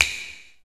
PRC SNAPS 0F.wav